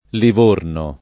liv1rno] top. — il porto di L. (Tosc.), fino al ’400, anche Livorna [liv1rna] — il comune piem., già L. Vercellese, dal 1863 L. Piemonte e oggi, dal 1924, Livorno Ferraris [liv1rno ferr#riS]